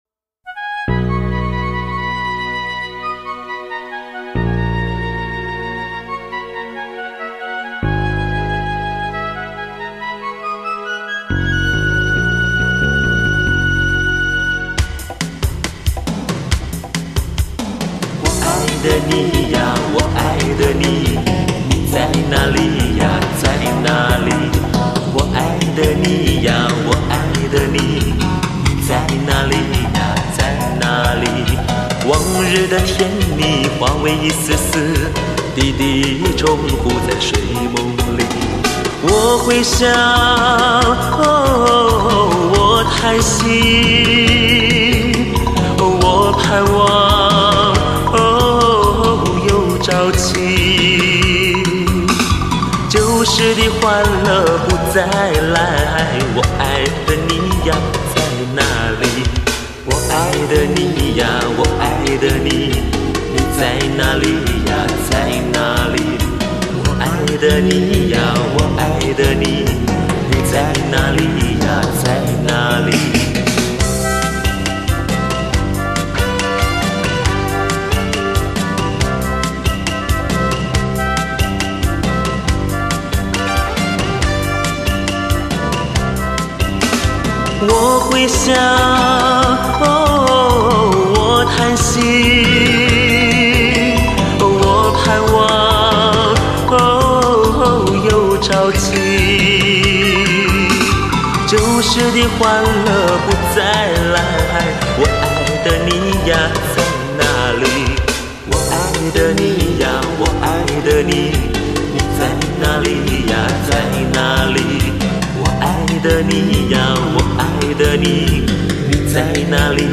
类型: HIFI试音